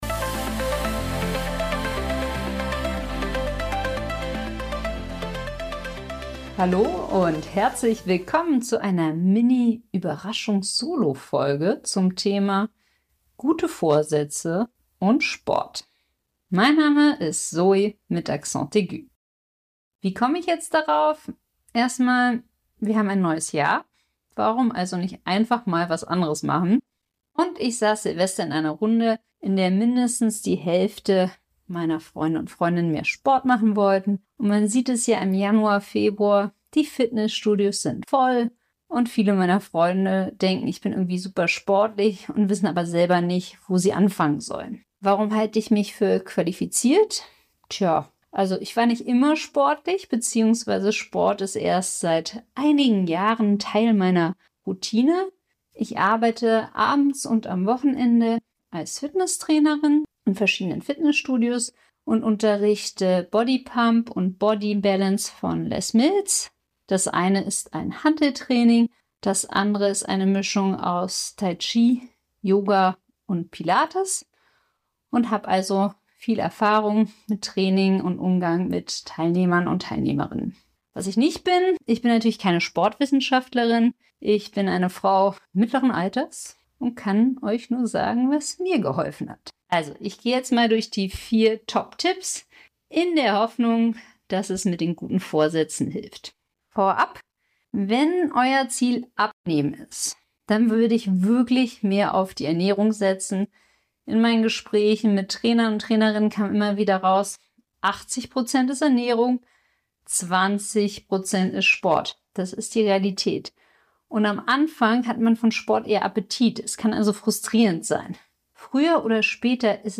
Zum Jahreswechsel gibt es eine Mini-Solo Folge zum Thema gute Vorsätze und Sport. Mit meinen Top vier Tipps zum Durchhalten: Logistik und Lage sind entscheidend Routine und Gewohnheiten Macht es euch schön.